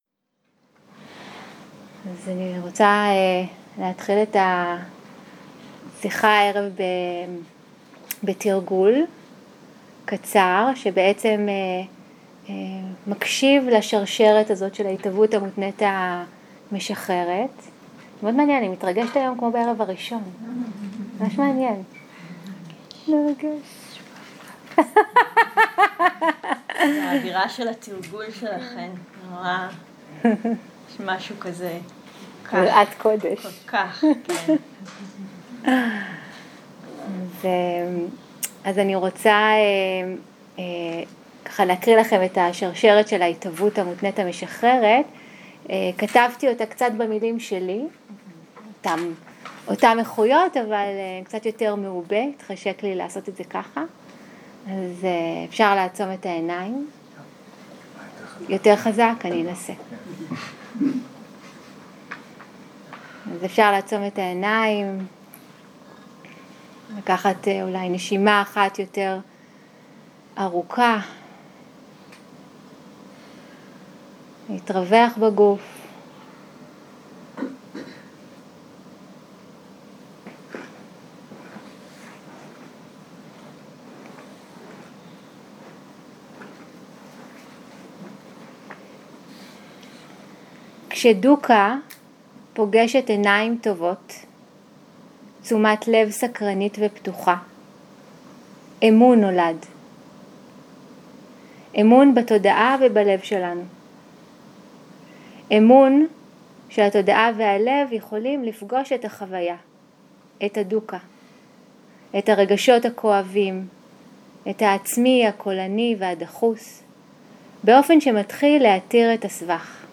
סוג ההקלטה: שיחות דהרמה שפת ההקלטה